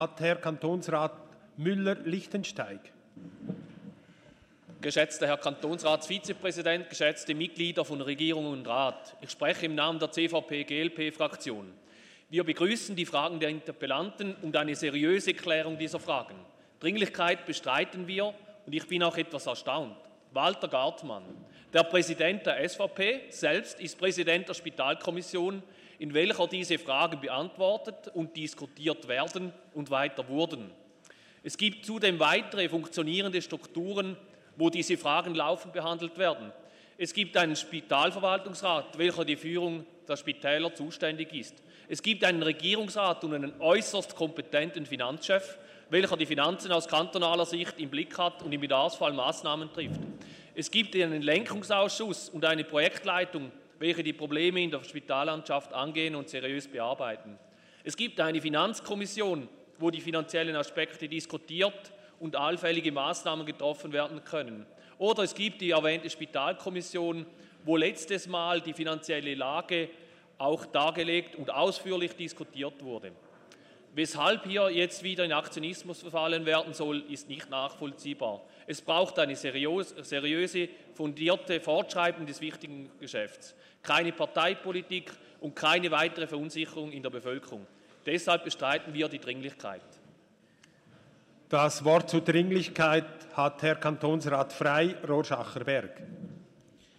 Session des Kantonsrates vom 23. und 24. April 2019